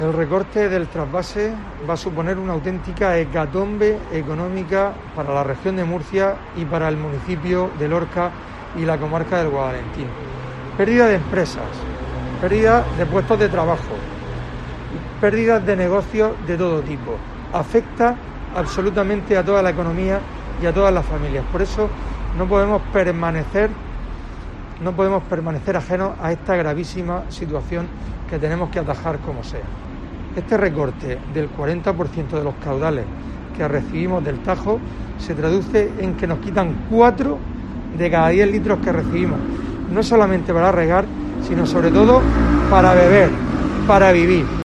Fulgencio Gil, portavoz del PP en Lorca